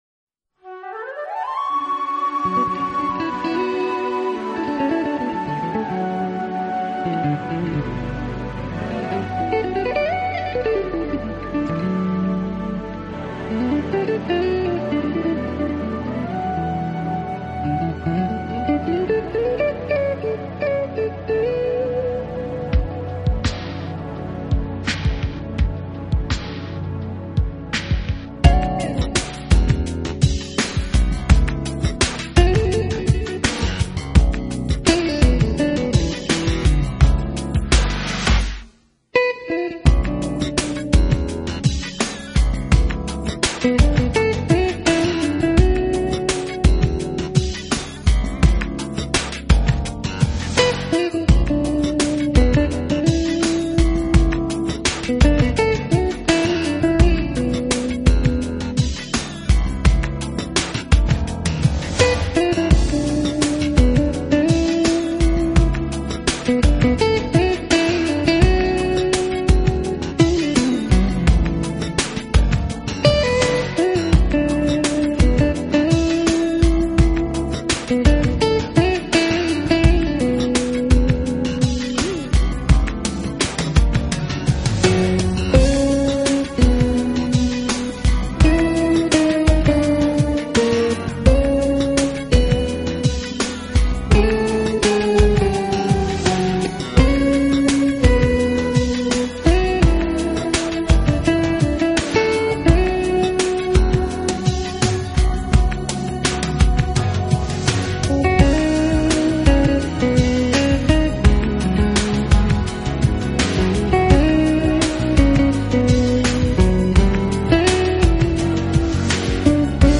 音乐类型：Jazz
guitar,  keyboards
Recorded at The Carriage House, Stamford, Connecticutt;